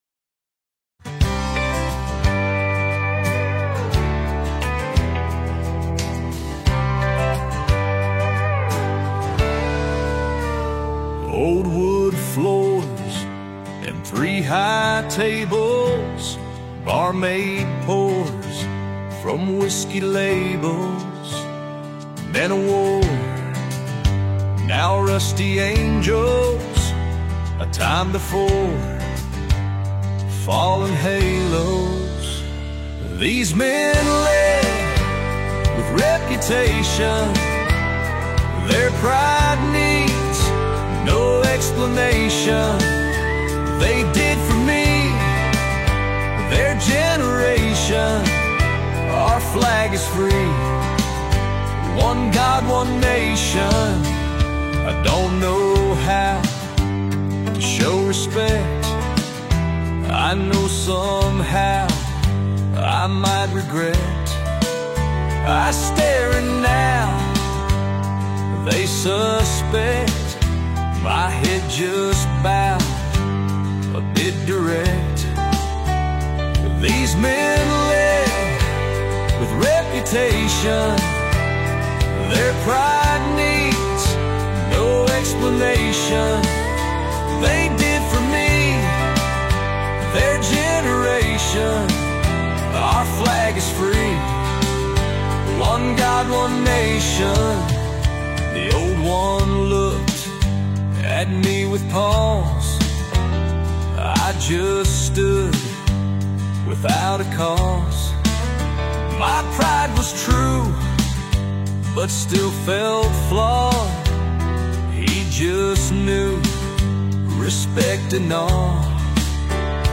AI(Music)